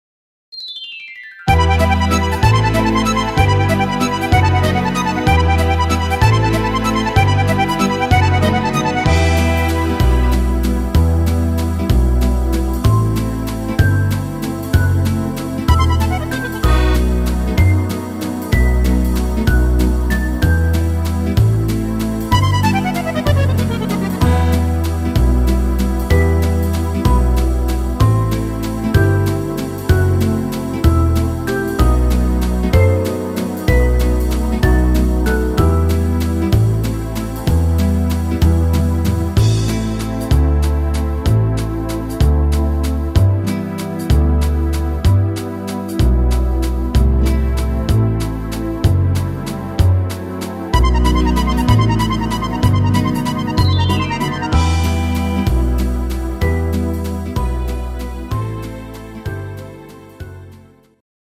instr. Akkordeon